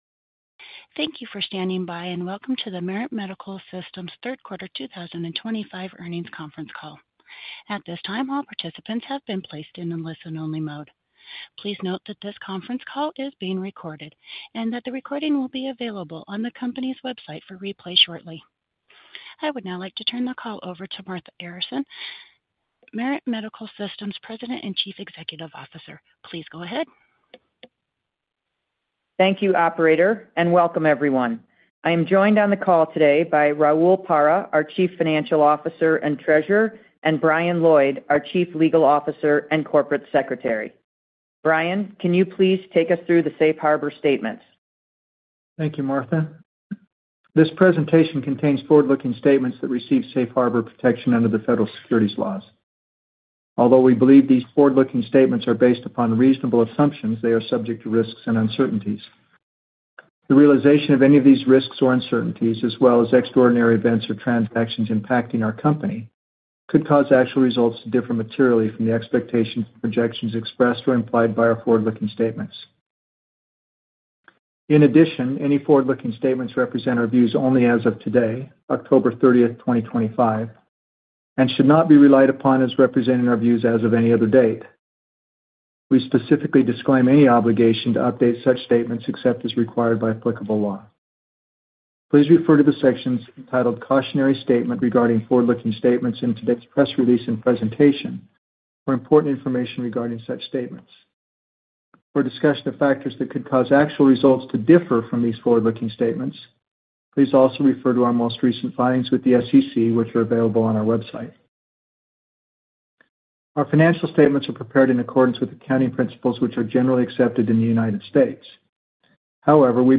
MMSI-Q3-2025-EarningsCall-Audio.mp3